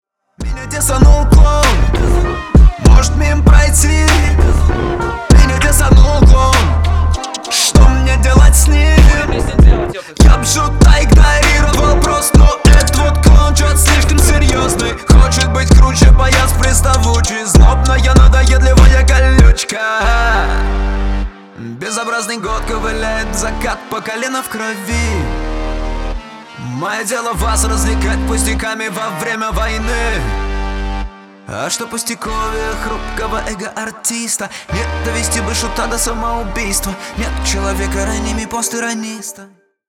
• Качество: 320, Stereo
русский рэп
качающие
diss